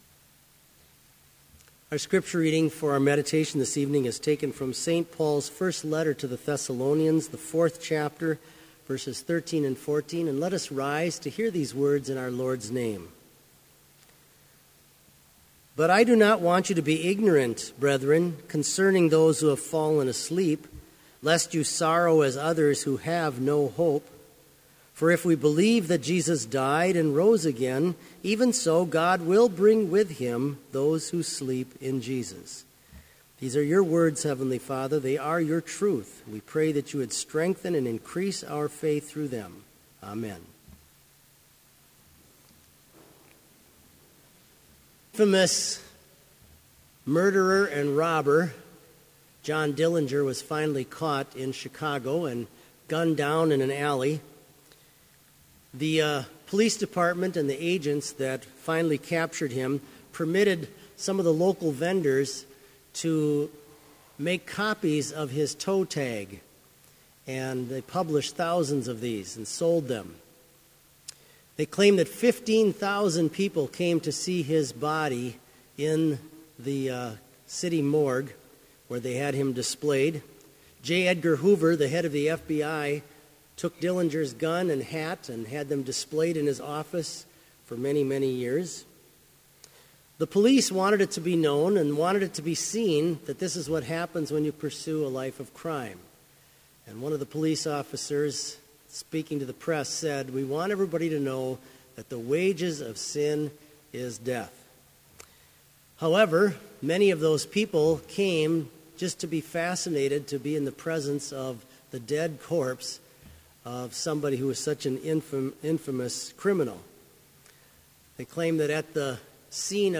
• Prelude
• Homily
• The Collect, Benedicamus (choir), etc.
• Postlude
This Vespers Service was held in Trinity Chapel at Bethany Lutheran College on Wednesday, November 4, 2015, at 5:30 p.m. Page and hymn numbers are from the Evangelical Lutheran Hymnary.